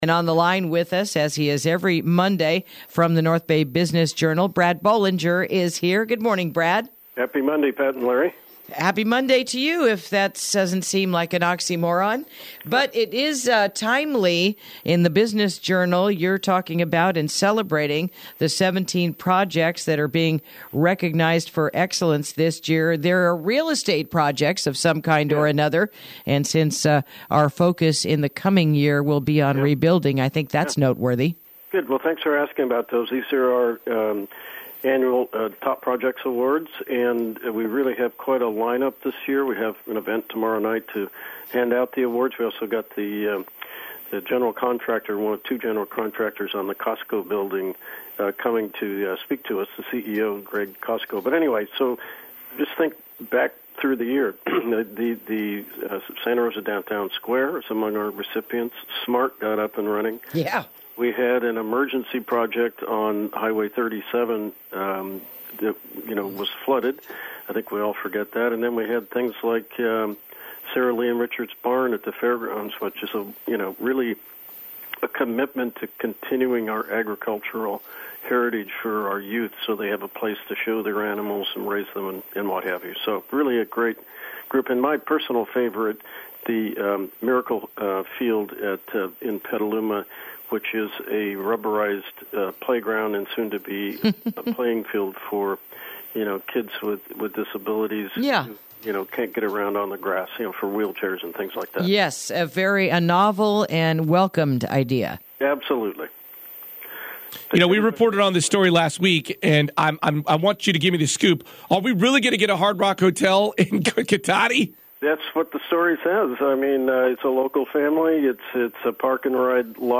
Interview: Takin’ Care of Business